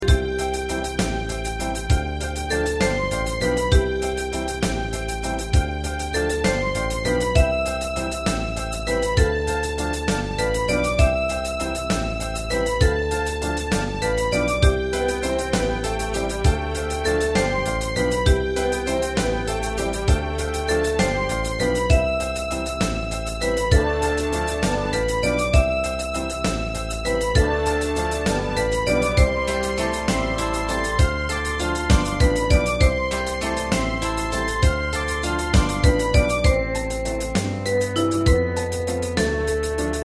大正琴の「楽譜、練習用の音」データのセットをダウンロードで『すぐに』お届け！
Unison musical score and practice for data.